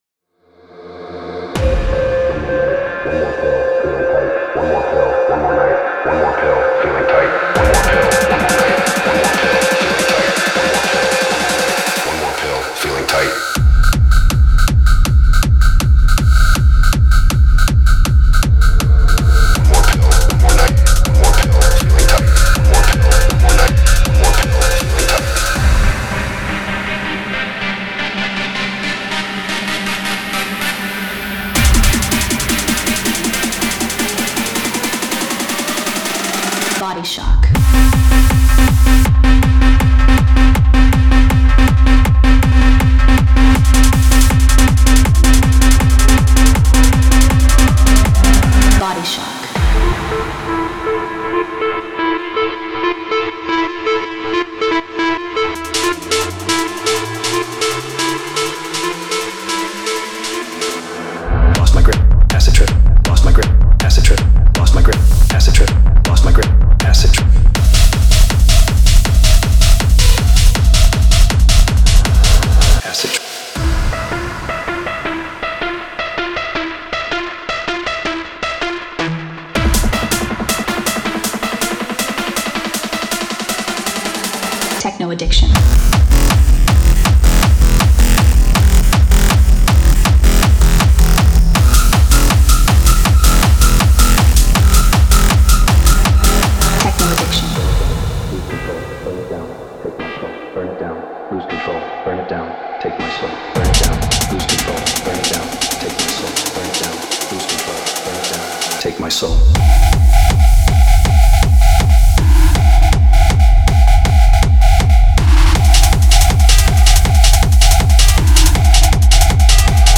Genre:Techno
テクノプロデューサーの皆さん、ハードテクノの relentless（容赦ない）なドライブに備えましょう！
絶え間ない高エネルギーの激しさを追求するハードテクノのために設計された
デモサウンドはコチラ↓
160 bpm
27 Full Drum Loops, Kick&Snare Loops, Top Loops
13 Vocal Loops